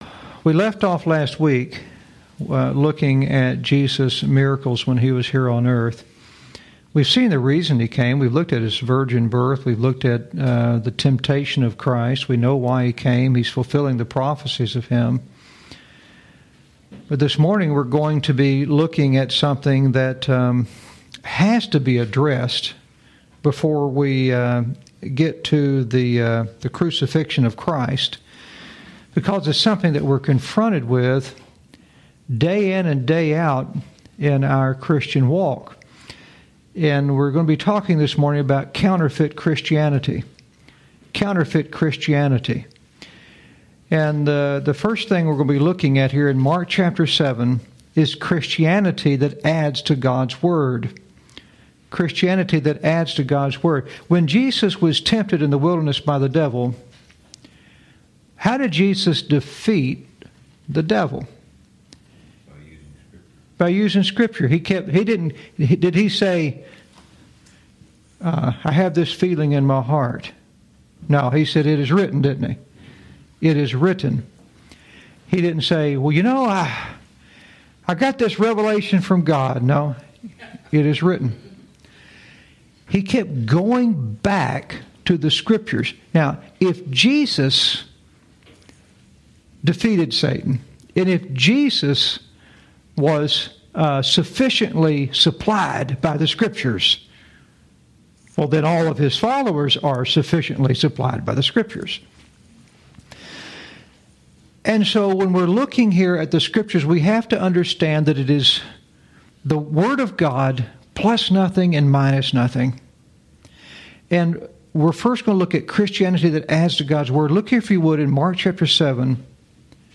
Lesson 36